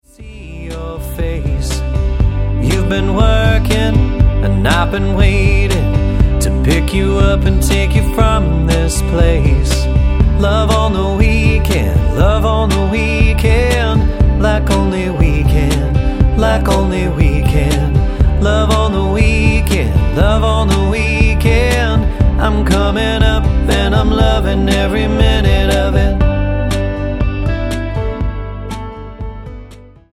Tonart:G Multifile (kein Sofortdownload.
Die besten Playbacks Instrumentals und Karaoke Versionen .